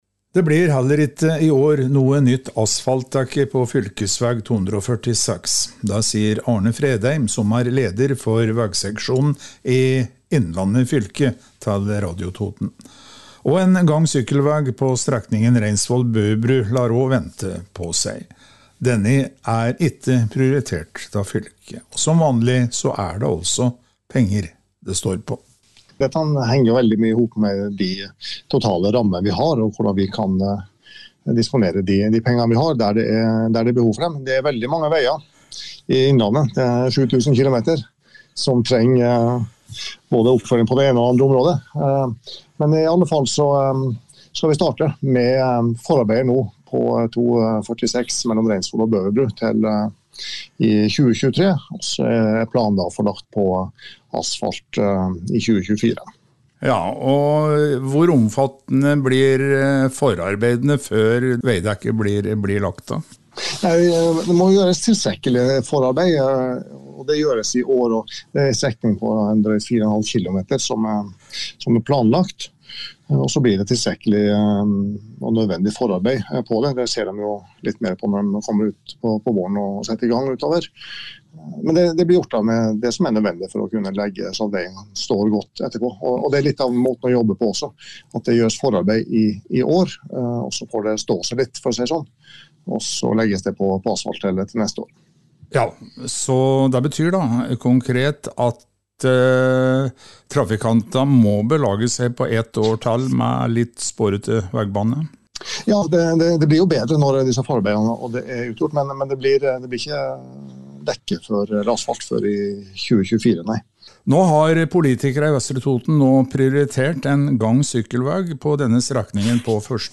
Innslag